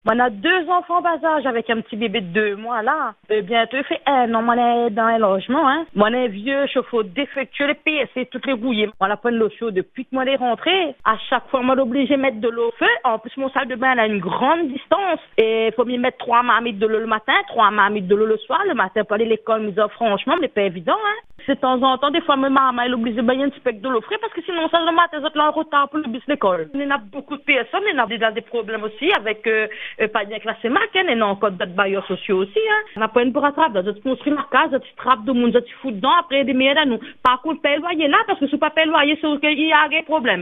Fatiguée et découragée, cette mère de famille raconte aujourd’hui son calvaire :